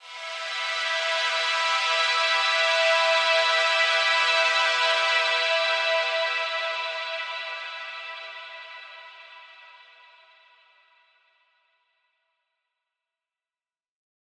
SaS_HiFilterPad07-E.wav